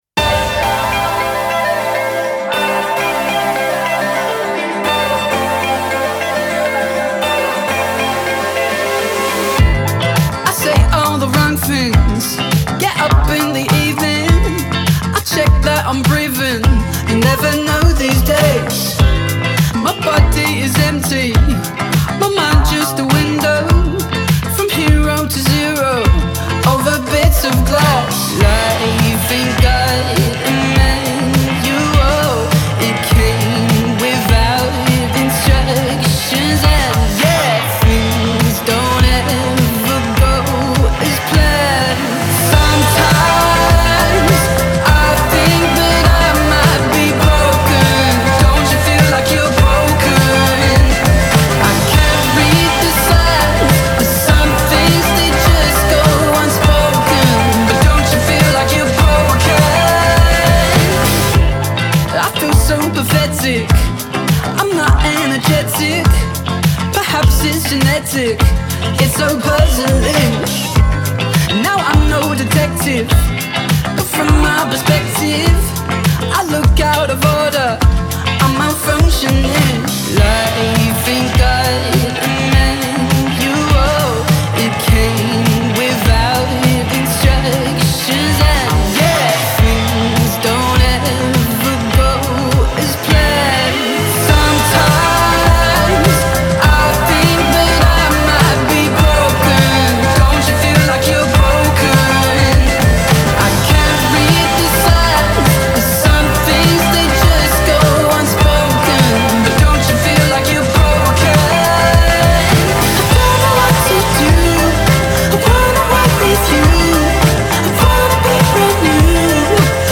BPM102-102
Audio QualityPerfect (High Quality)
Indie Pop song for StepMania, ITGmania, Project Outfox
Full Length Song (not arcade length cut)